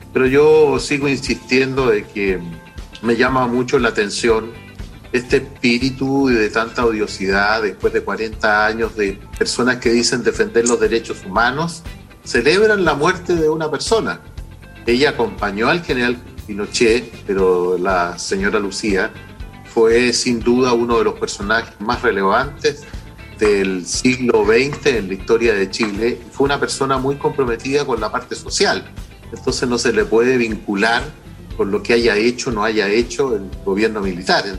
En conversación con Radio Sago, el parlamentario de la UDI defendió el legado de la viuda del General Augusto Pinochet, asegurando que fue uno de los personajes más relevantes del siglo XX en la historia de Chile. Moreira puntualizó que Lucía Hiriart fue una persona muy comprometida con la parte social, entonces no se le puede vincular con lo que haya hecho o no el gobierno militar.